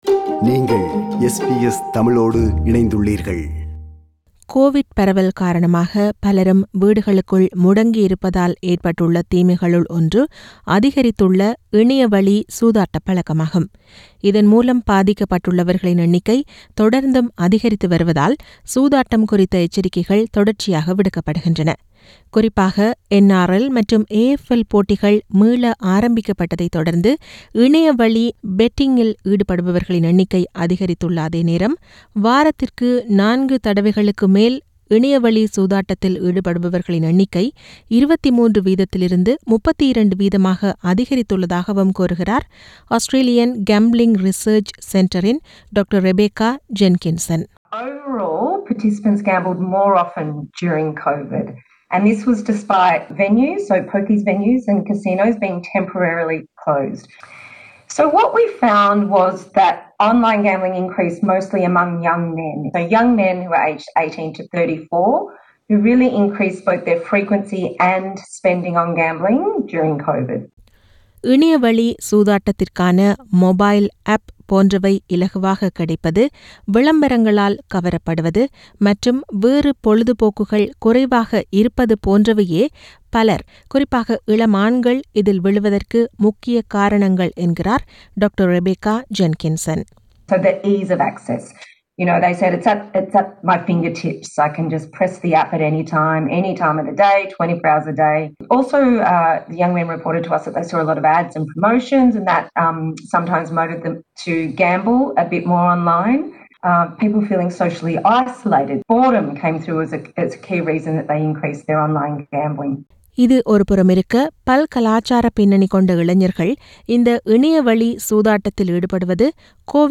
Increases in online gambling and wagering app downloads reflect a shift in behaviour, with a worrying spike in gambling issues among younger Australians. Feature